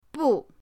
bu4.mp3